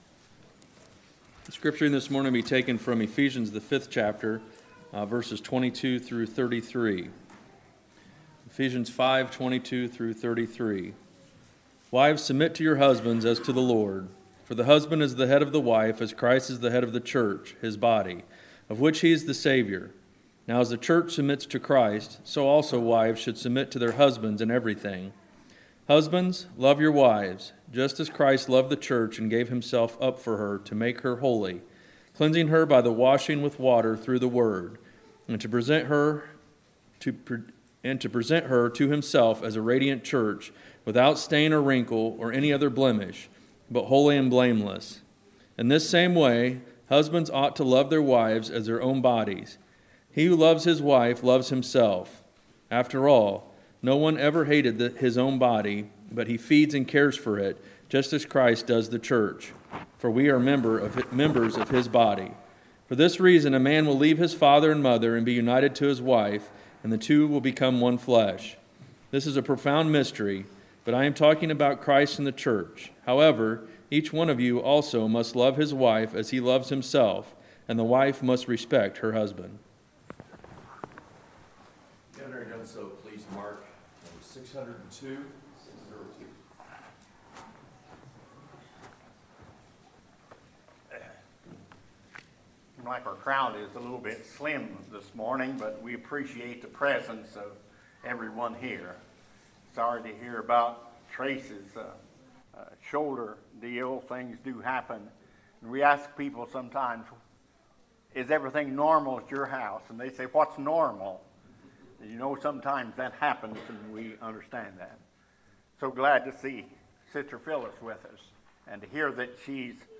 Sermons, November 18, 2018 – Gadsden Church of Christ